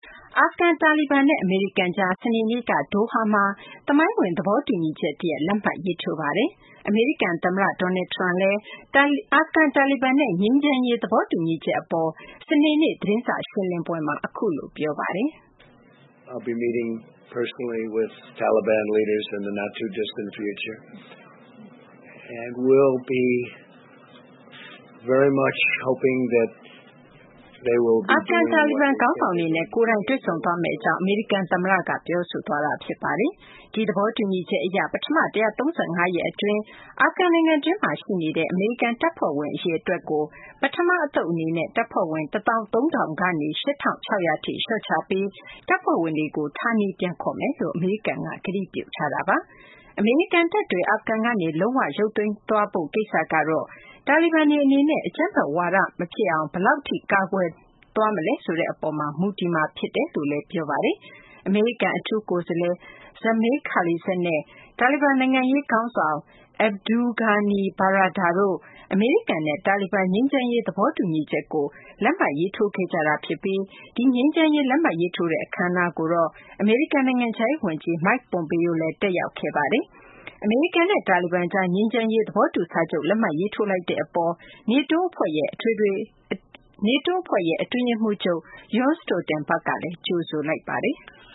အာဖဂန် တာလီဘန်နဲ့ အမေရိကန်ကြား စနေနေ့က ကာတာနိုင်ငံ ဒိုဟာမြို့မှာ သမိုင်းဝင် သဘောတူညီချက်တရပ် လက်မှတ်ရေးထိုးပါတယ်။ အမေရိကန်သမ္မတ Donald Trump ကလည်း အာဖဂန် တာလီဘန်နဲ့ ငြိမ်းချမ်းရေး သဘောတူညီချက်အပေါ် စနေနေ့ သတင်းစာရှင်းလင်းပွဲမှာ အခုလို ပြောပါတယ်။